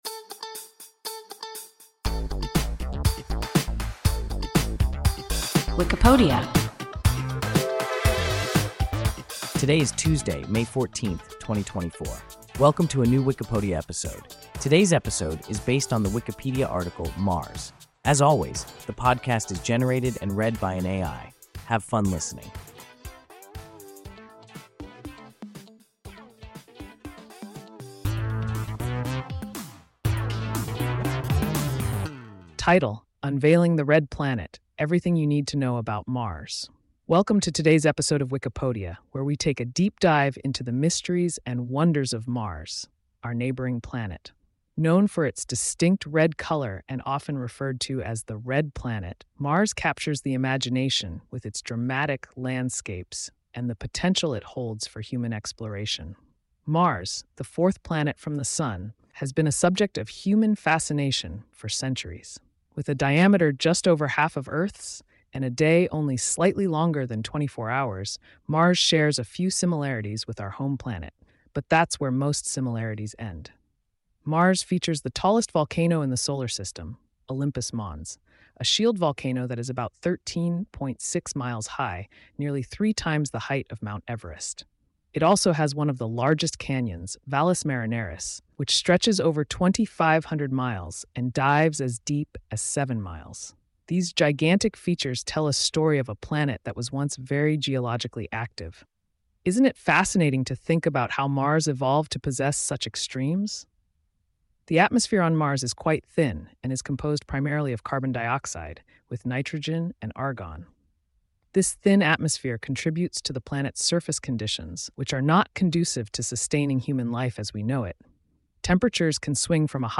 Mars – WIKIPODIA – ein KI Podcast